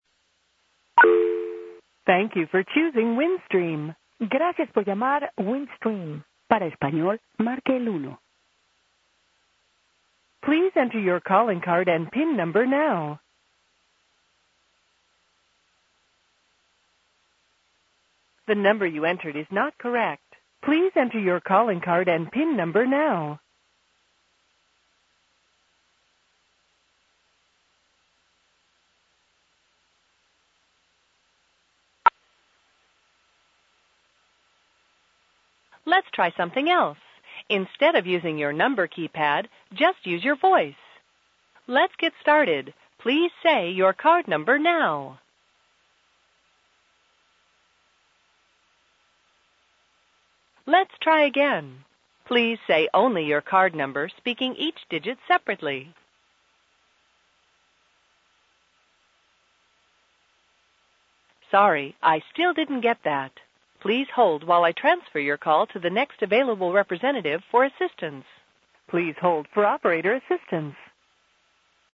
The following are examples of other local or long distance telephone company operator service platforms sounds and recordings.